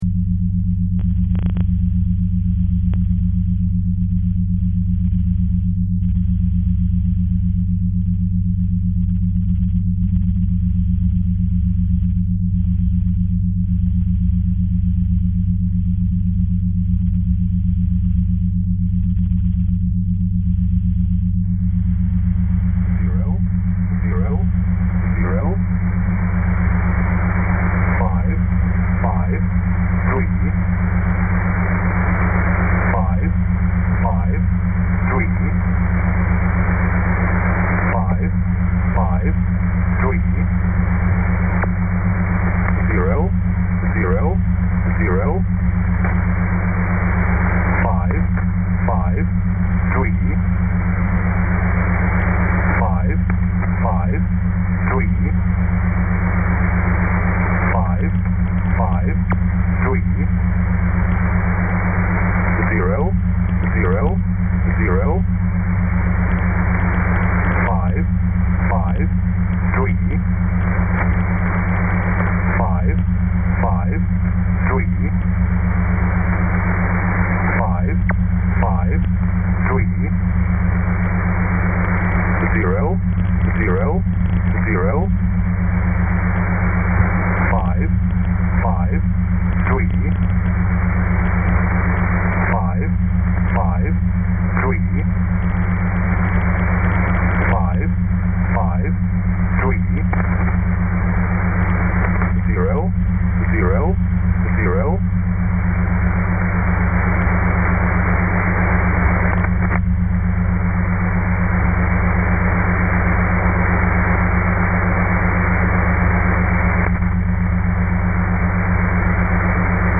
(requires headphones)